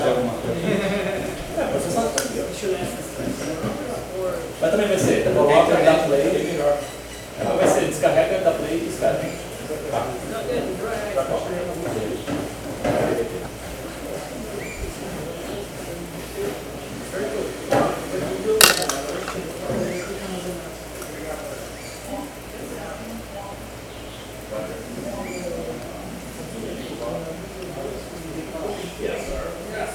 rainforest
bird-voices